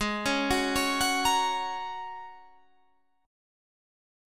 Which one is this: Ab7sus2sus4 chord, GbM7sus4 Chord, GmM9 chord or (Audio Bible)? Ab7sus2sus4 chord